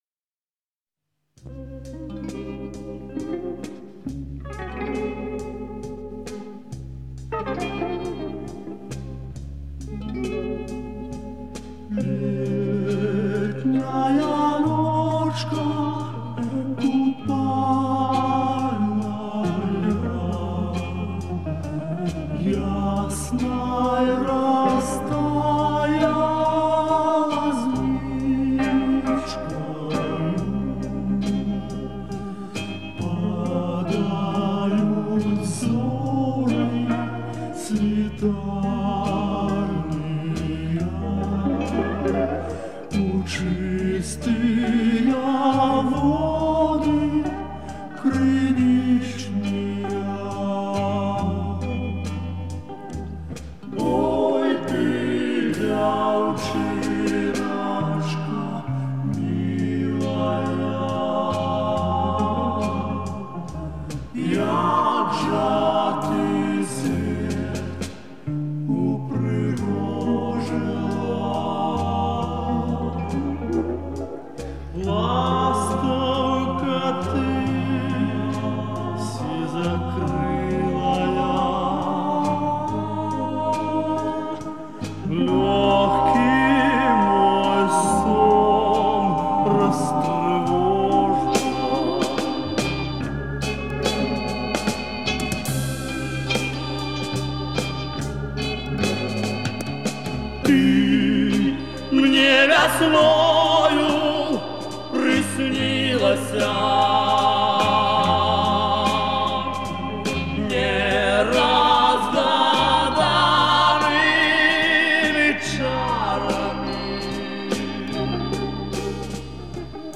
Это видимо с пластинки...